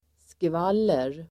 Uttal: [skv'al:er]